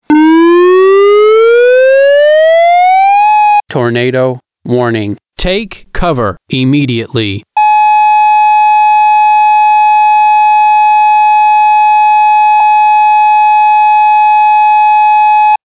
Tornado Warning
The tornado warning tone will sound like this:
Tornado-Warning-MP3